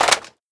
magout.mp3